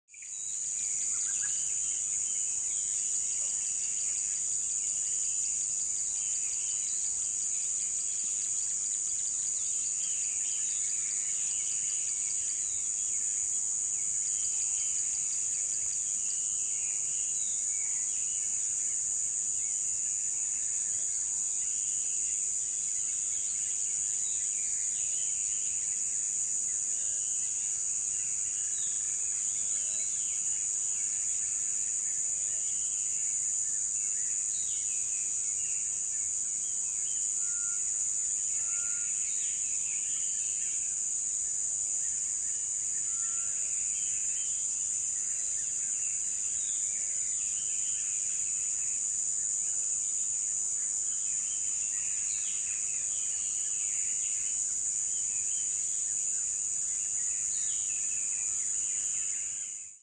دانلود آهنگ رودخانه 3 از افکت صوتی طبیعت و محیط
دانلود صدای رودخانه 3 از ساعد نیوز با لینک مستقیم و کیفیت بالا
جلوه های صوتی